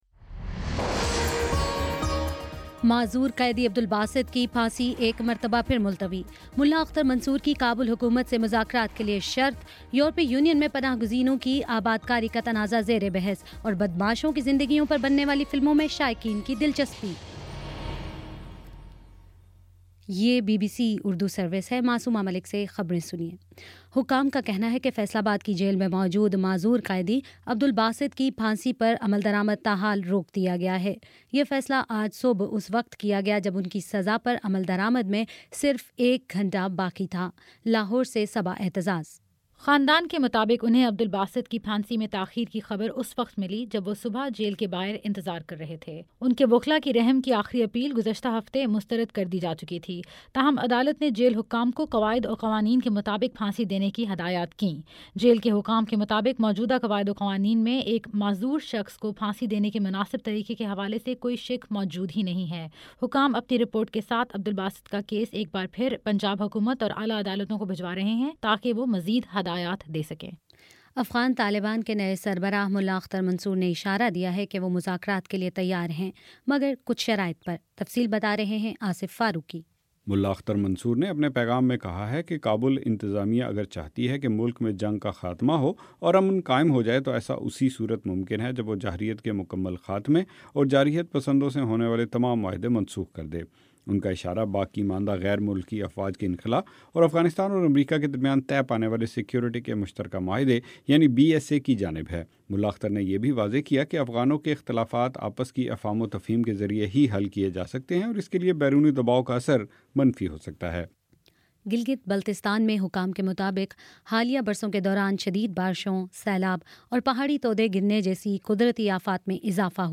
ستمبر22 : شام سات بجے کا نیوز بُلیٹن